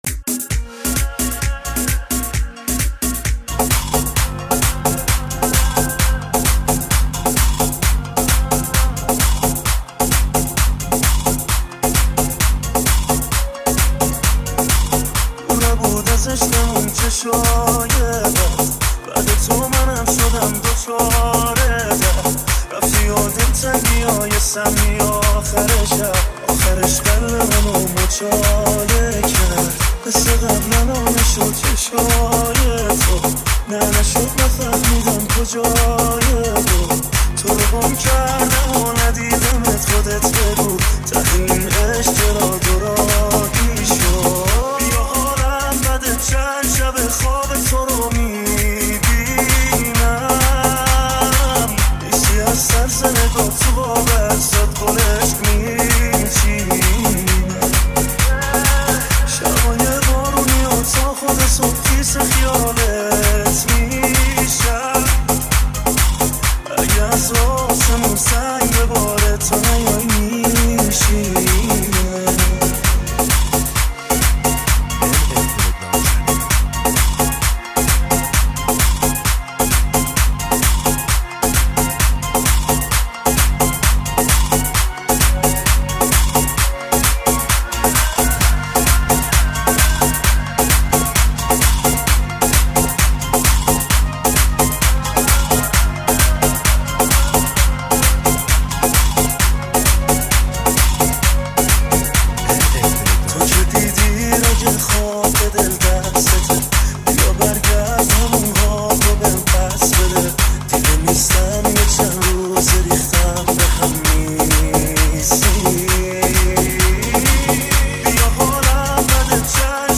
تند بیس دار